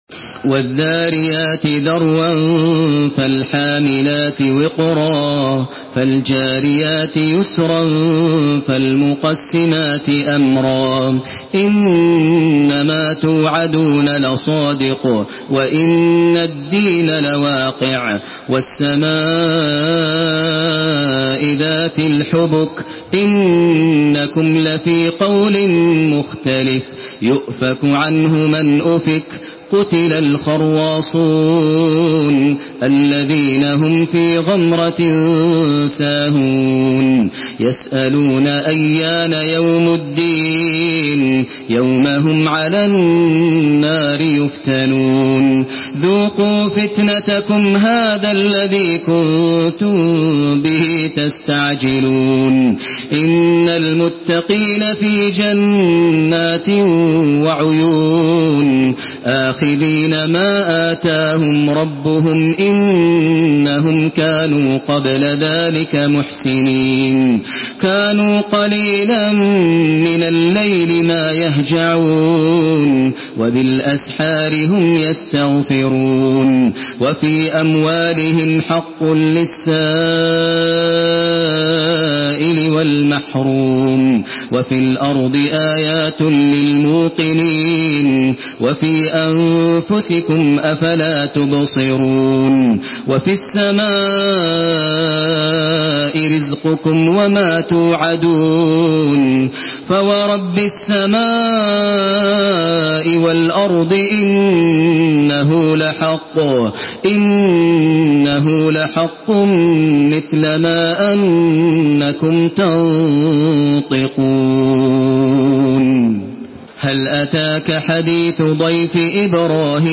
Une récitation touchante et belle des versets coraniques par la narration Hafs An Asim.
Makkah Taraweeh 1432
Murattal Hafs An Asim